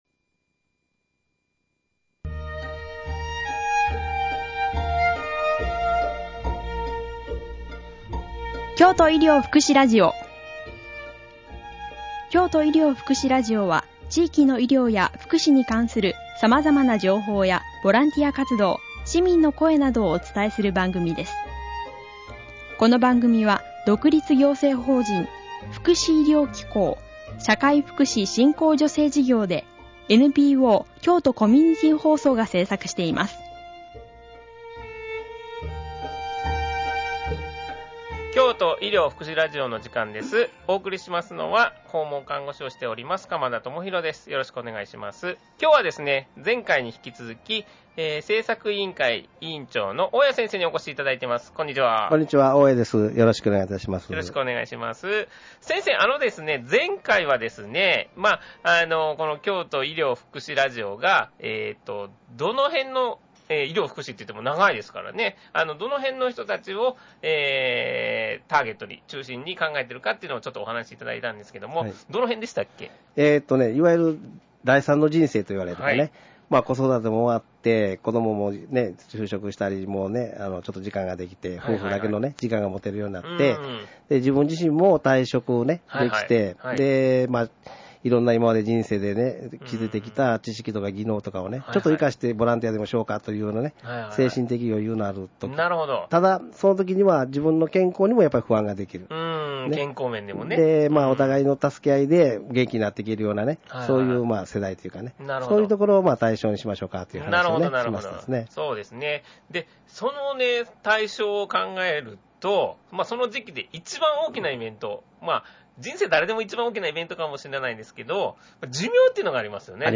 詩の朗読